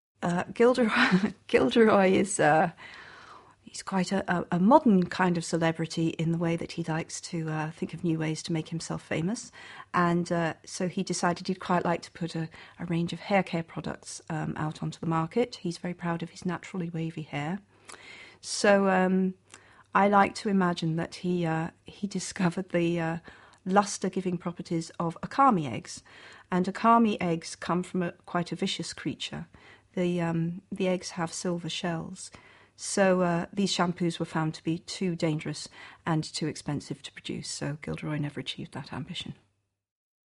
ฟัง เจ.เค.โรว์ลิ่ง เปิดเผยความลับเบื้องหลังผลิตภัณฑ์ดูแลเส้นผมของกิลเดอรอย ล็อกฮาร์ต